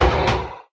hit4.ogg